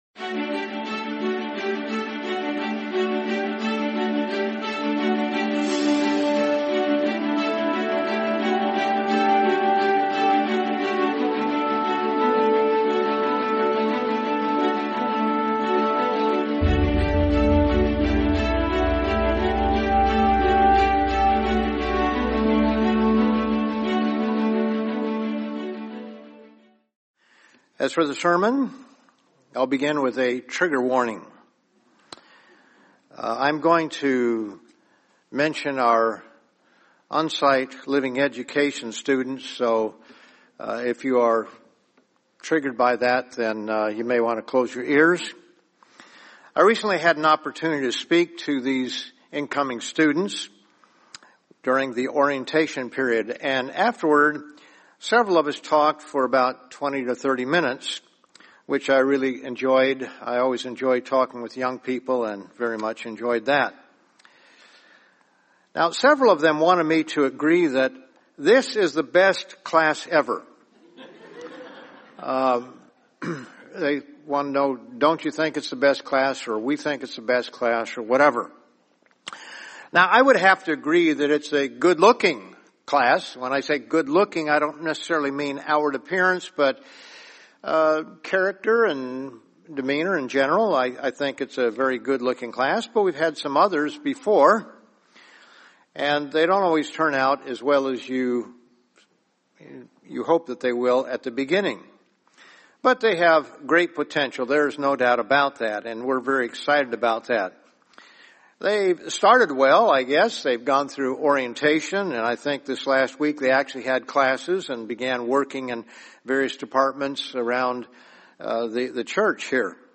Get Serious About Your Marriage | Sermon | LCG Members